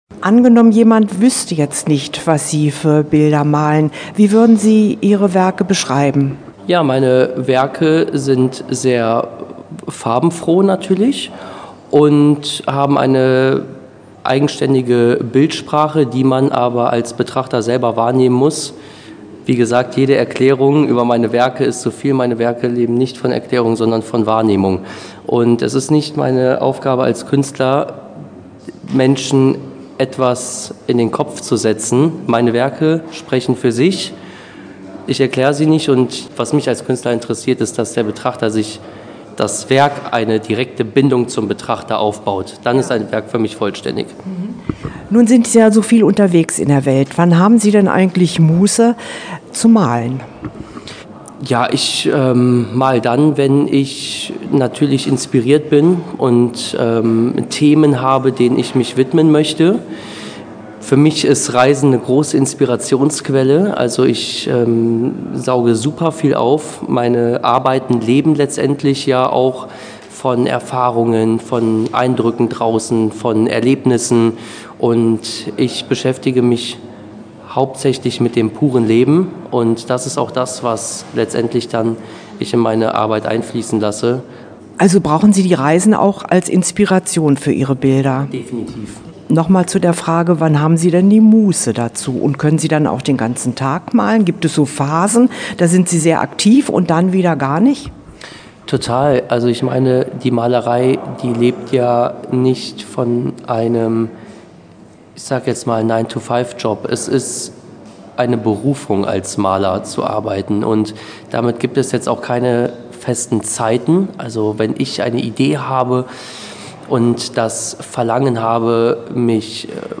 Interview-Ausstellung-Loewentraut.mp3